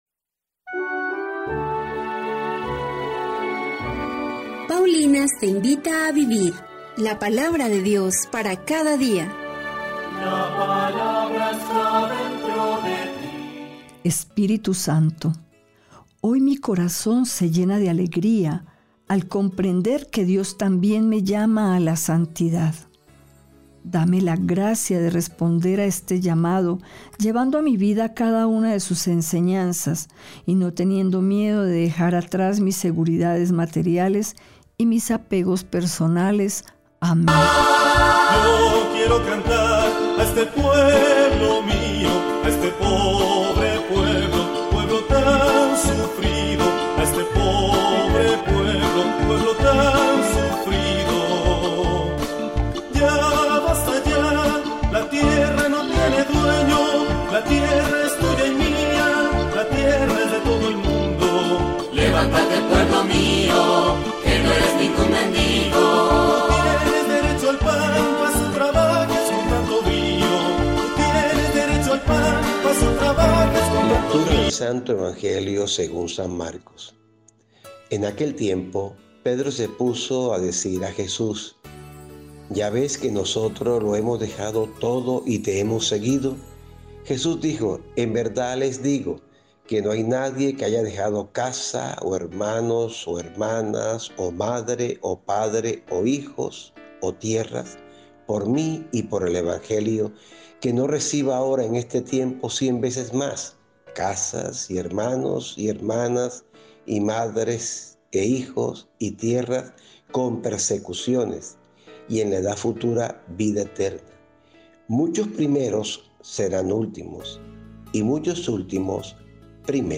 Liturgia diaria
Primera Lectura